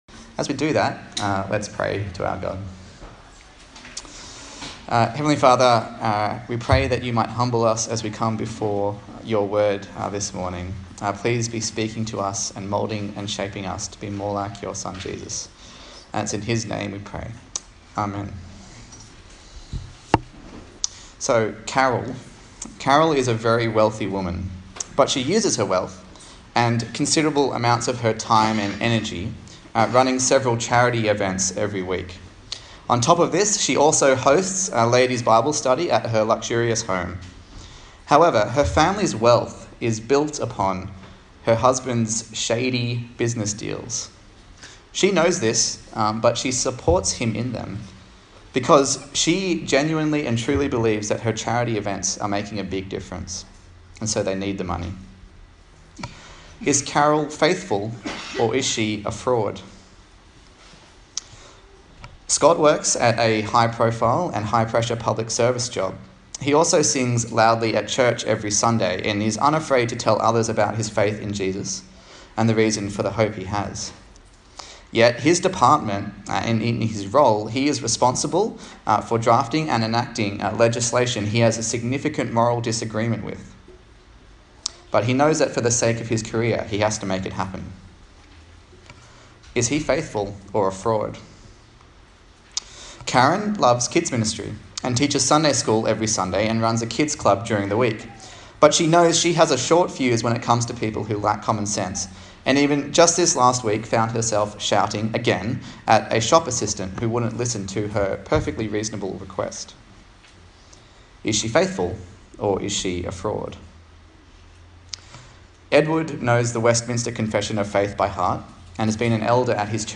Jonah Passage: Jonah 2 Service Type: Sunday Morning A sermon in the series on the book of Jonah